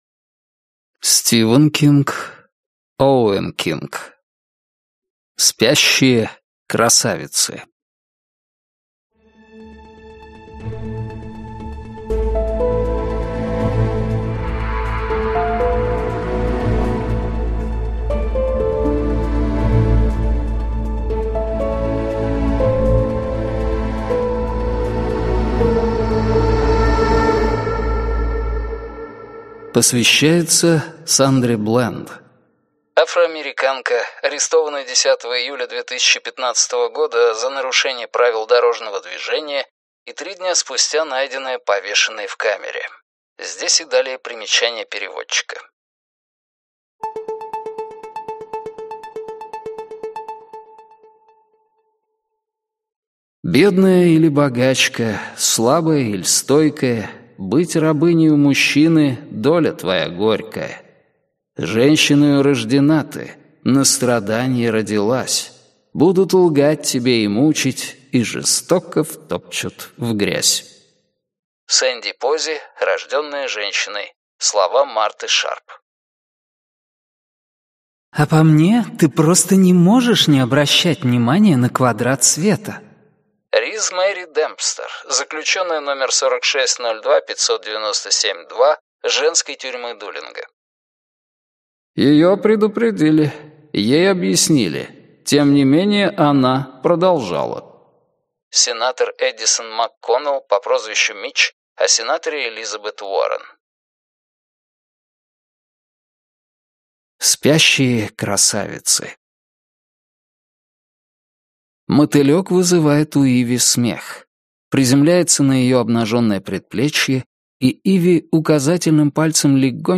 Аудиокнига «Спящие красавицы» в интернет-магазине КнигоПоиск ✅ в аудиоформате ✅ Скачать Спящие красавицы в mp3 или слушать онлайн